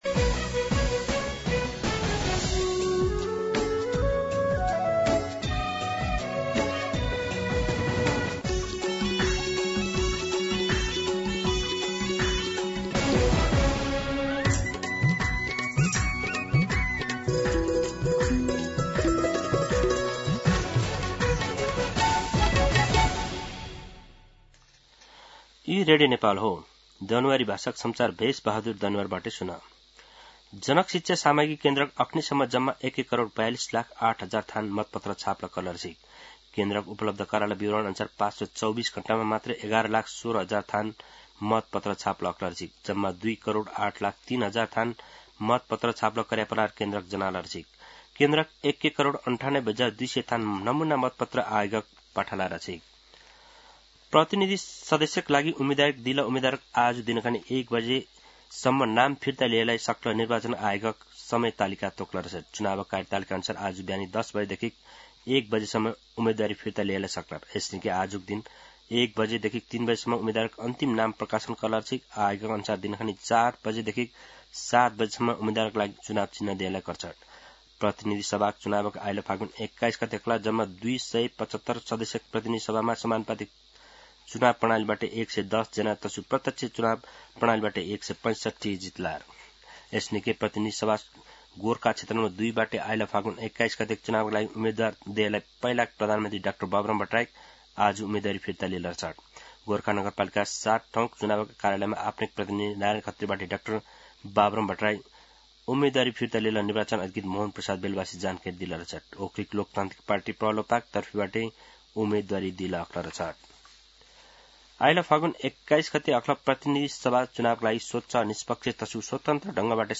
दनुवार भाषामा समाचार : ९ माघ , २०८२
Danuwar-News-10-9.mp3